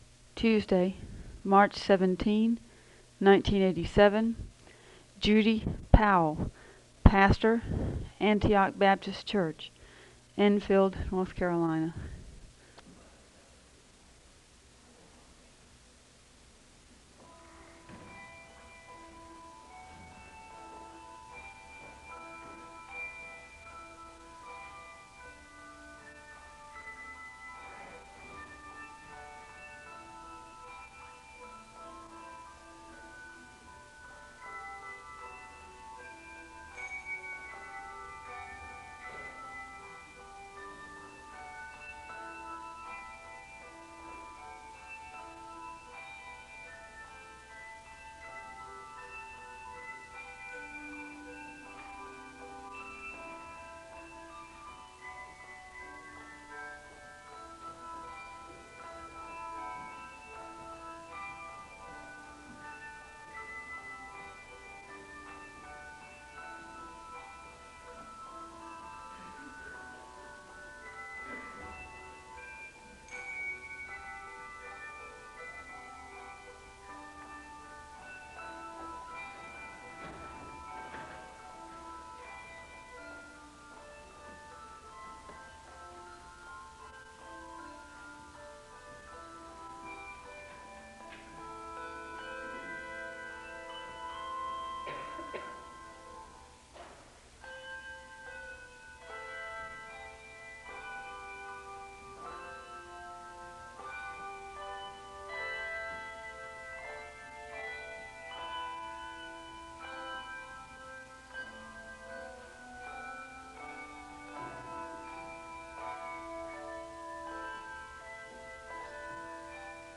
The service begins with organ music (0:00-4:34).
Prayer concerns are shared with the congregation and there is a moment of prayer (4:35-7:58).
The choir sings an anthem (10:11-14:46).
There is a charge and a blessing to close the service (38:03-38:24).